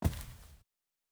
Footstep Carpet Walking 1_05.wav